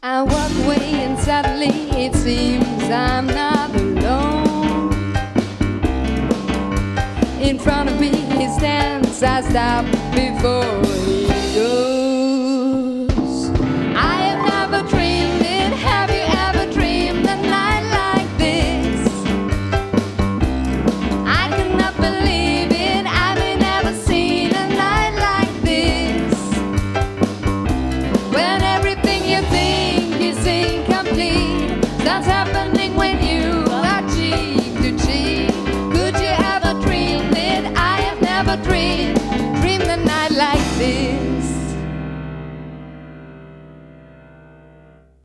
Gesang, Gitarre
(Wiener Walzer)